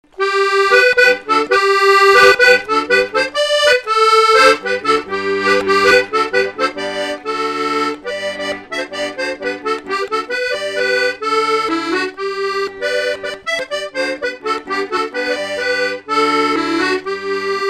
Enquête Arexcpo en Vendée
Pièce musicale inédite